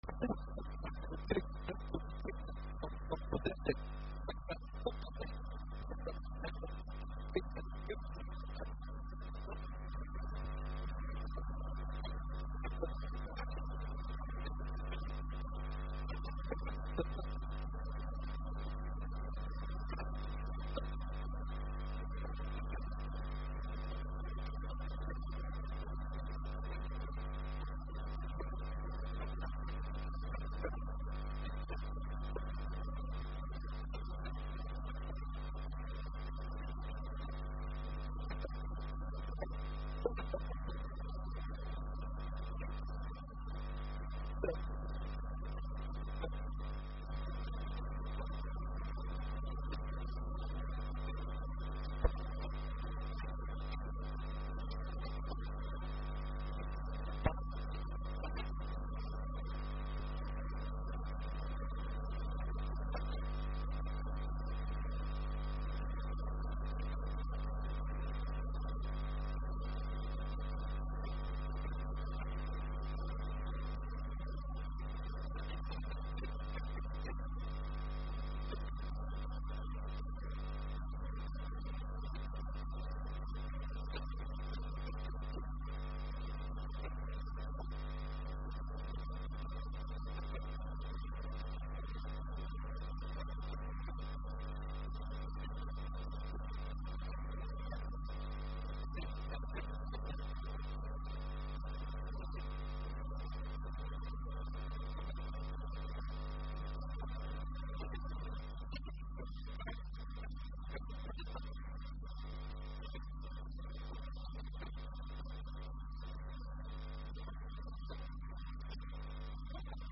Warbixinta